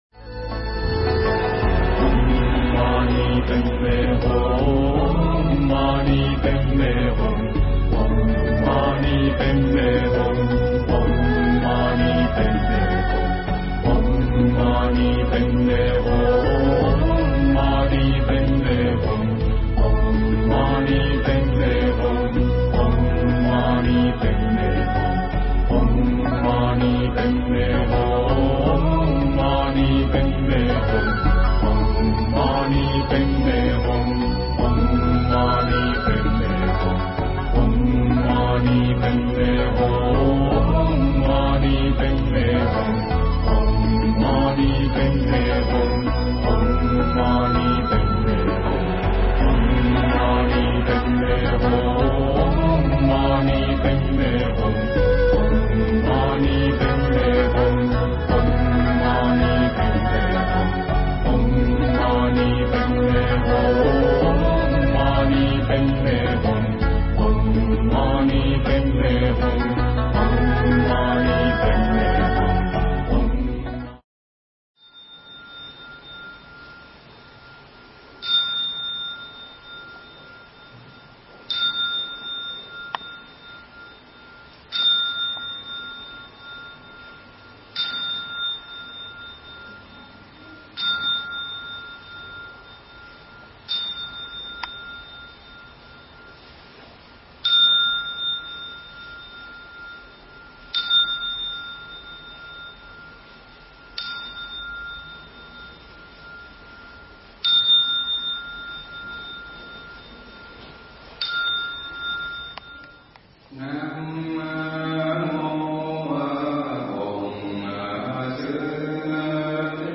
Mp3 Thuyết Pháp Cách Sống Vui
giảng tại St. Petersburg – Liên Bang Nga